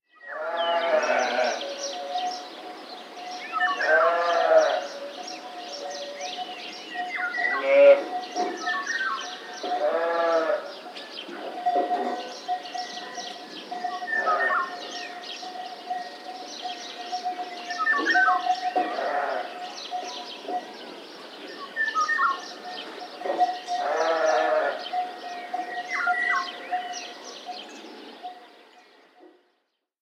Ambiente de campo con ovejas
ambiente
oveja
Sonidos: Animales
Sonidos: Rural